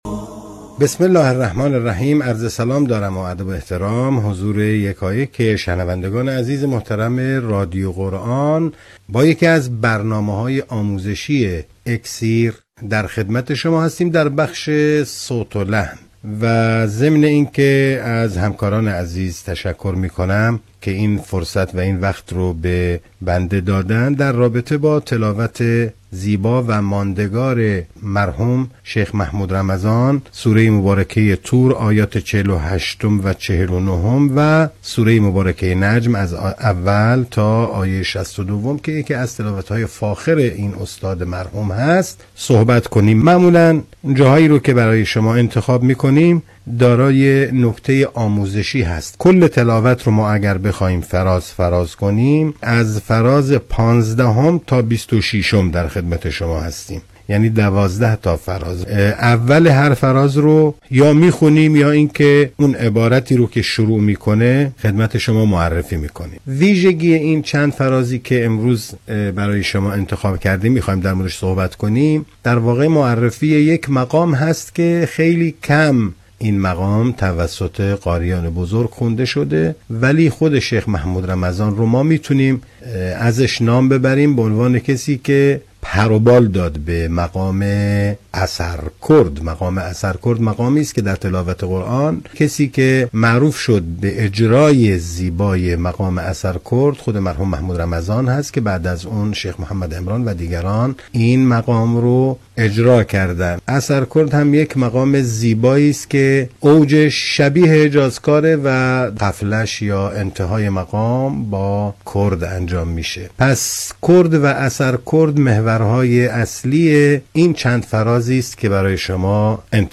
اثَرکُرد، مقام زیبایی است که اوجش شبیه «حجازکار» است و غفله یا انتهای مقام با کُرد است.